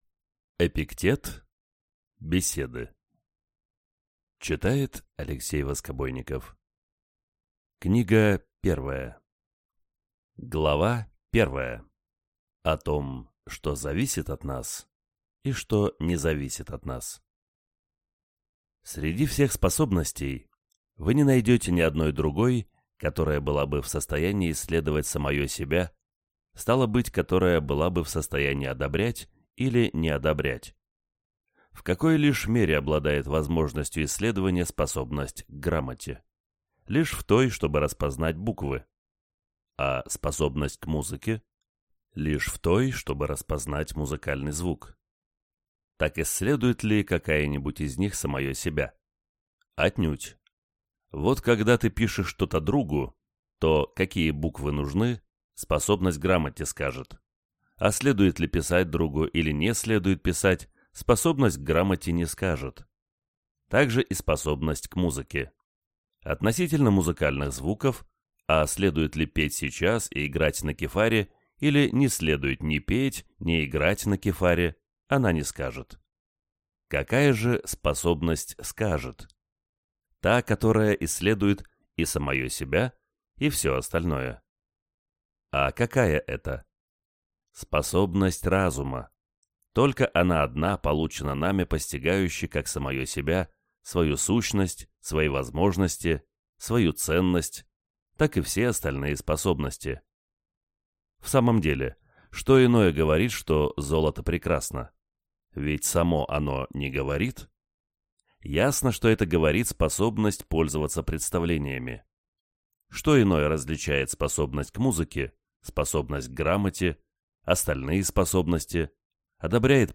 Аудиокнига Беседы | Библиотека аудиокниг